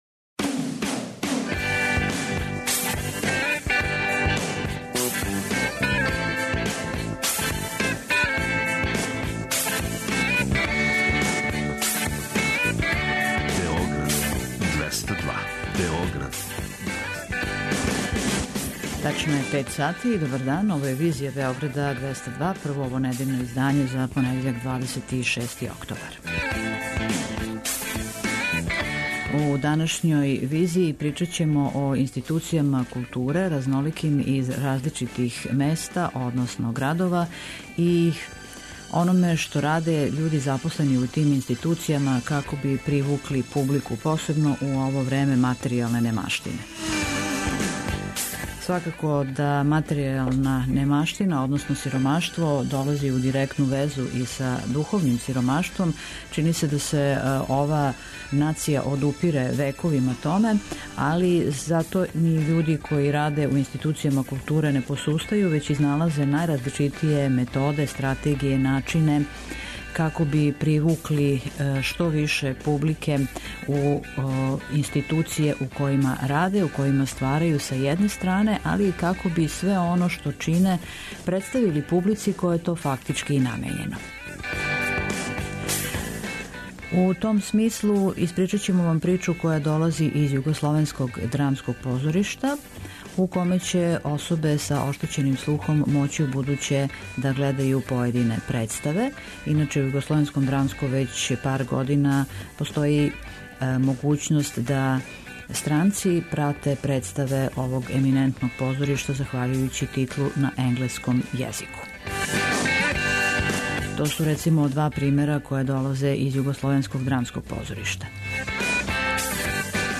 преузми : 55.36 MB Визија Autor: Београд 202 Социо-културолошки магазин, који прати савремене друштвене феномене.